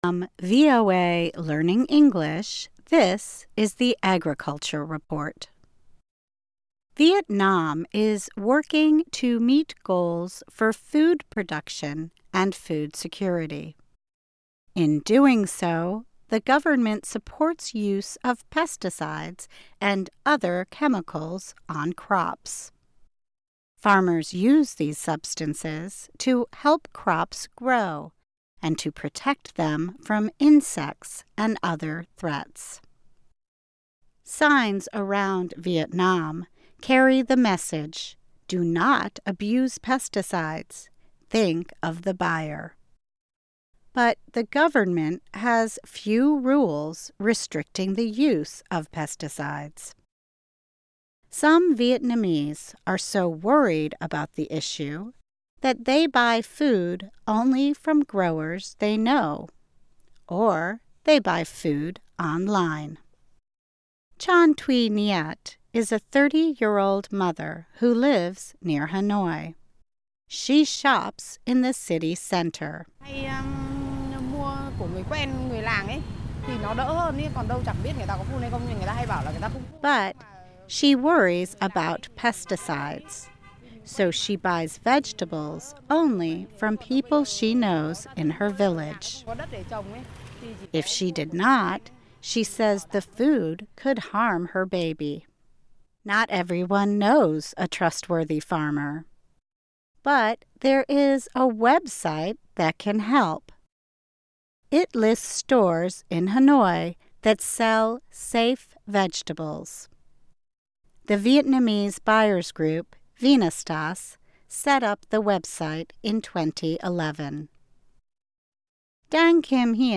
Learn English as you read and listen to a weekly show about farming, food security in the developing world, agronomy, gardening and other subjects. Our stories are written at the intermediate and upper-beginner level and are read one-third slower than regular VOA English.